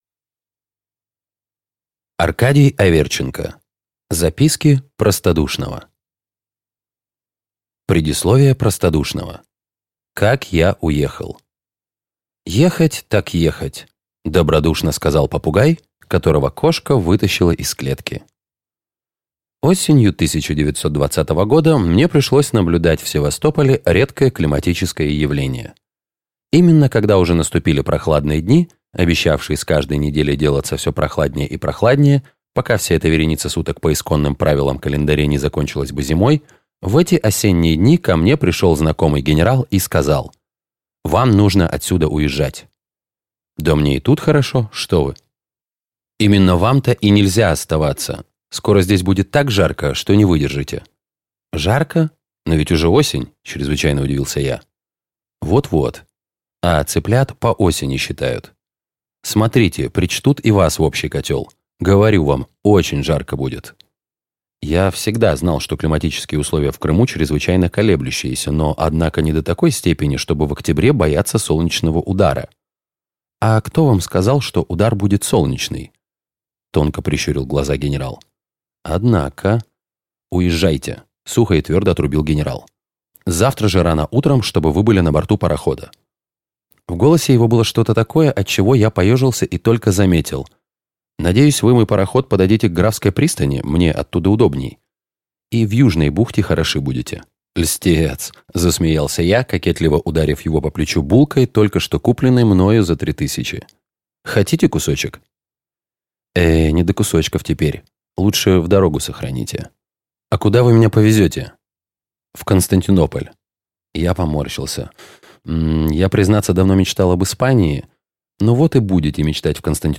Аудиокнига Записки простодушного | Библиотека аудиокниг